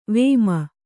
♪ vēma